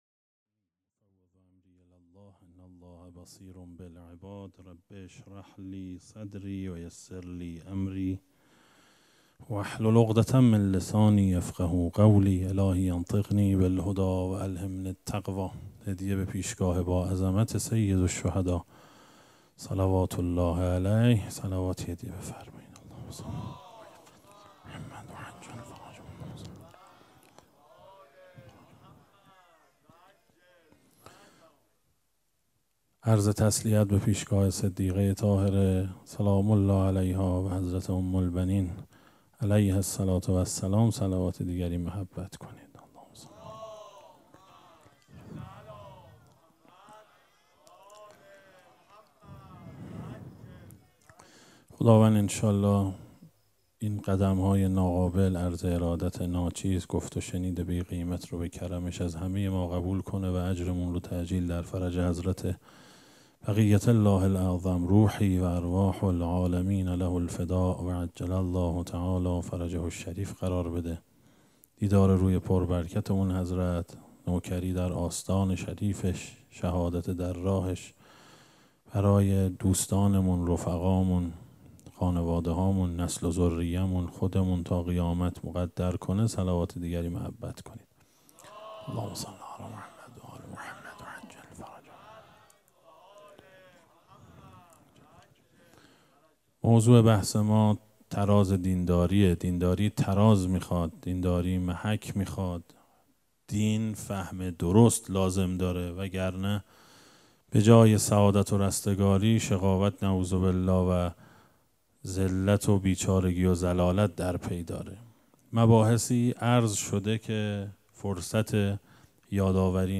سخنرانی
سخنرانی شب تاسوعا محرم
سبک اثــر سخنرانی